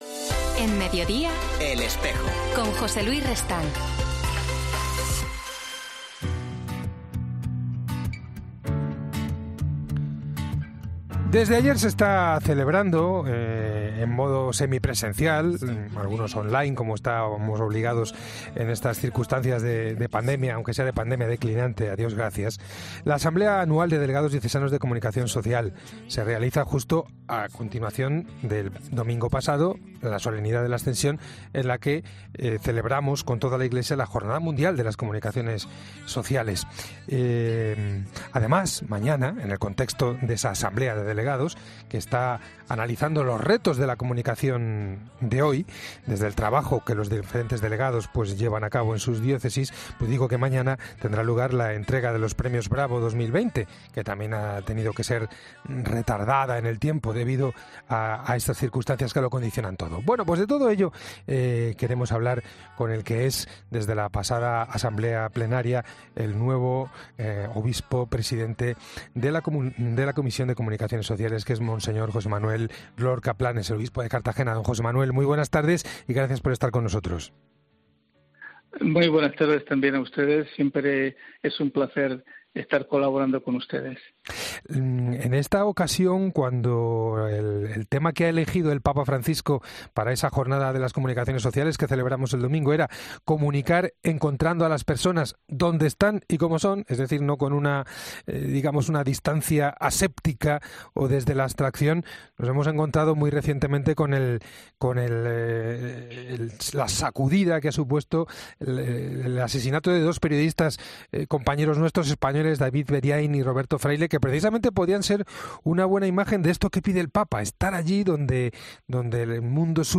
Para hablar de todo ello, hoy en 'El Espejo' hemos estado con monseñor José Manuel Lorca Planes, obispo de Cartagena y presidente de la Comisión Episcopal para las Comunicaciones Sociales de la Conferencia Episcopal Española.